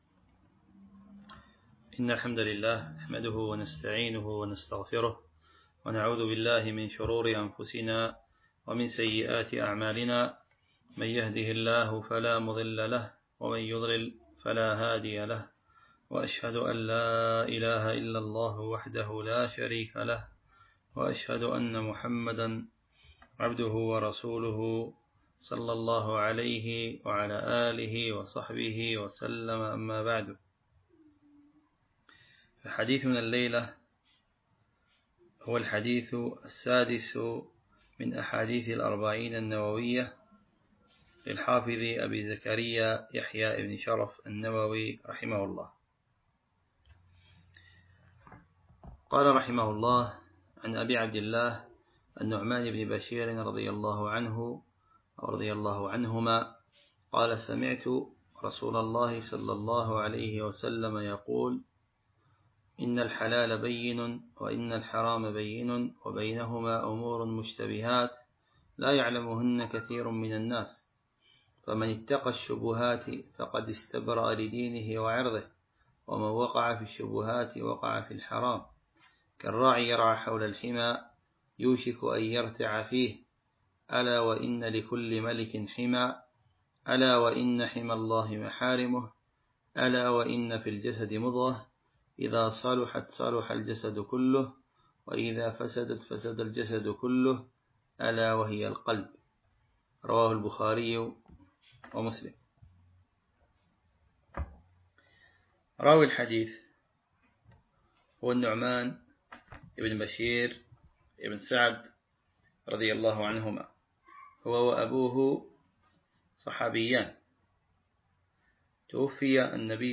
شرح الأربعون النووية الدرس 5